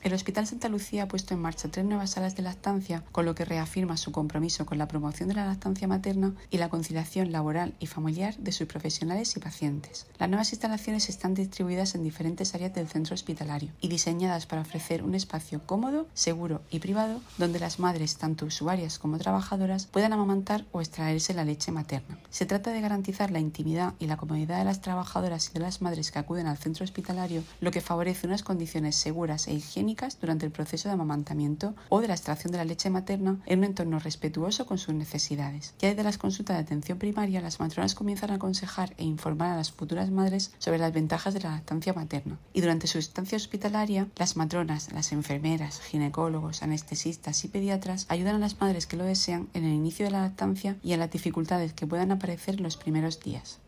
Sonido/ Declaraciones de la directora gerente del Servicio Murciano de Salud, Isabel Ayala, en su visita a las nuevas salas de lactancia del hospital Santa Lucía.